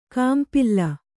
♪ kāmpilla